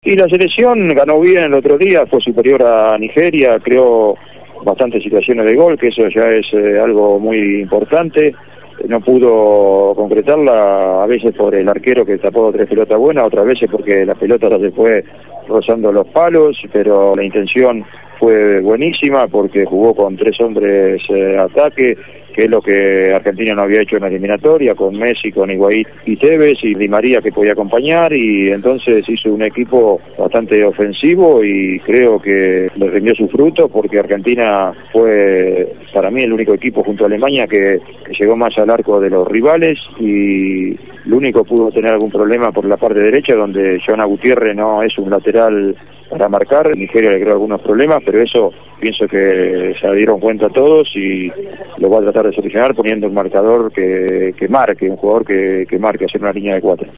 Ricardo Enrique Bochini, máximo ídolo histórico del Club Atlético Independiente y Campeón del Mundo en la Copa del Mundo de 1986, fue entrevistado